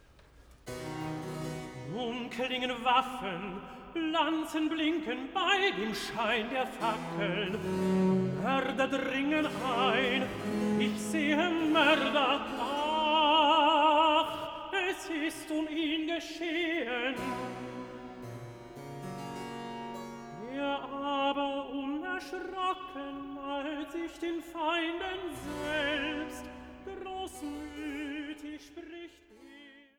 Passionskantate für Soli, Chor und Orchester
Aria (Sopran II) „Ein Gebeth um neue Stärke“